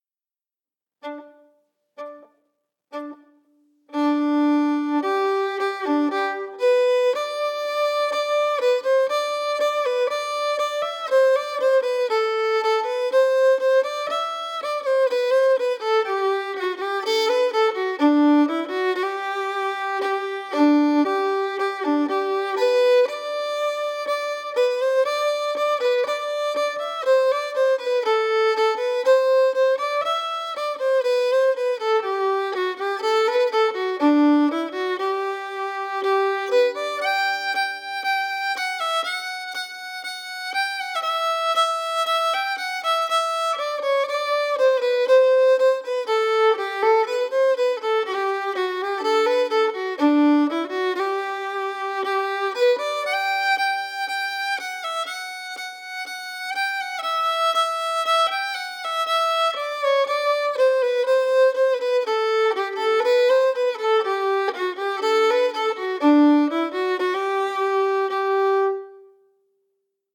Key: G
Form: Gånglåt or Walking tune
Source: Traditional
Appelbo-Ganglat-slow-audio.mp3